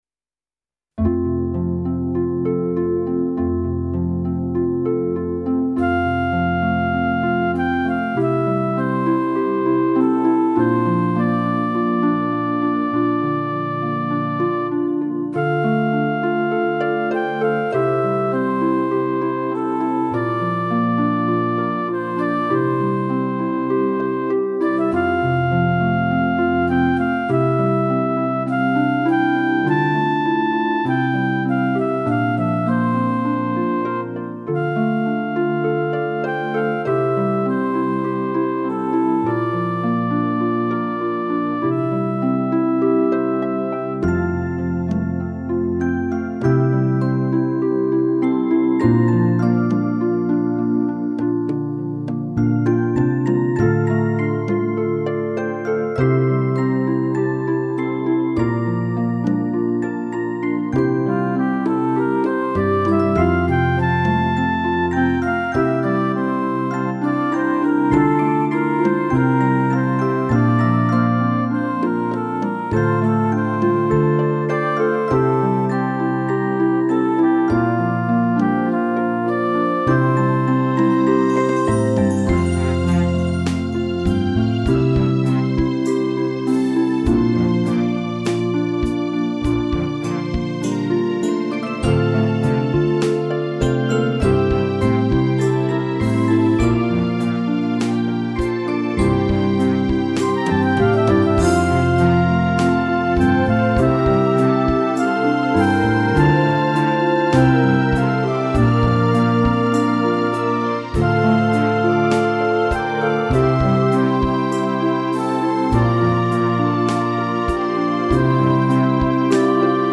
Instrumentation:Solo Flute with mp3 backing tracks
a beautiful and accessible contemplative